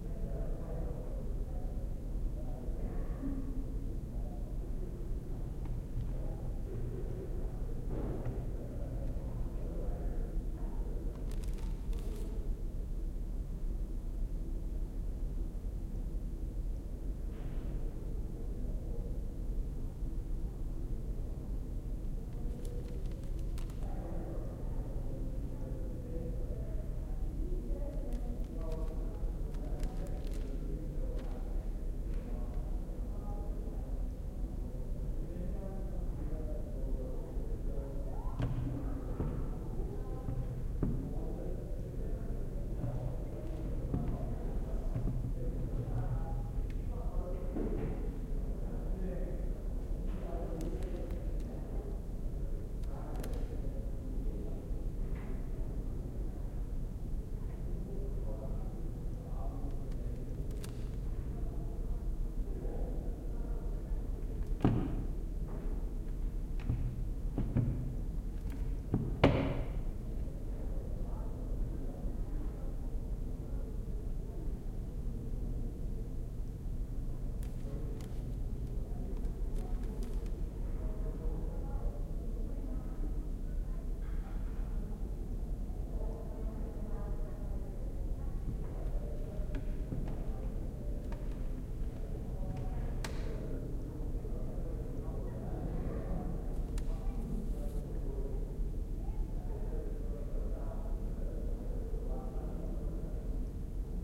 classroomLoop.ogg